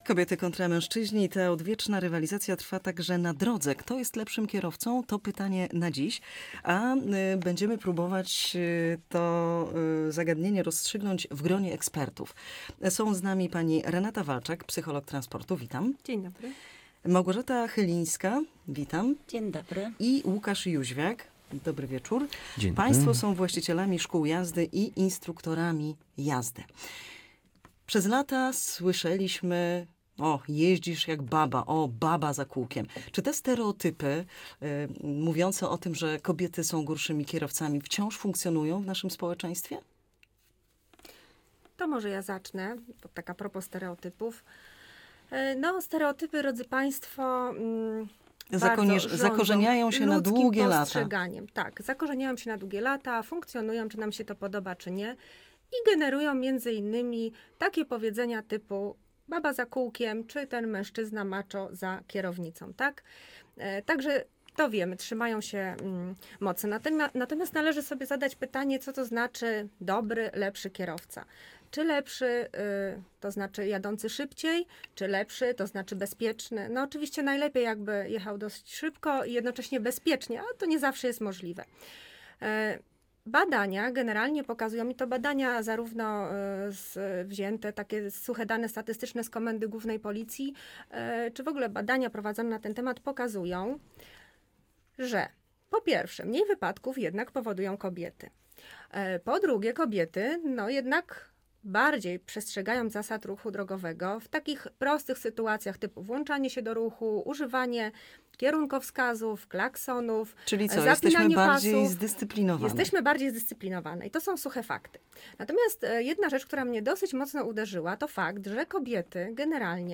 O tym rozmawialiśmy z psychologiem transportu i instruktorami jazdy.